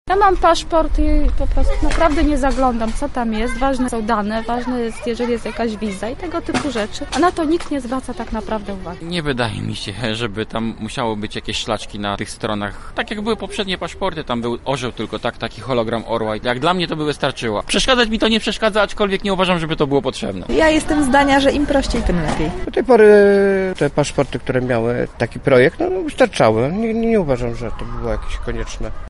Zapytaliśmy mieszkańców Lublina co sądzą o zmianach:
sonda-paszporty.mp3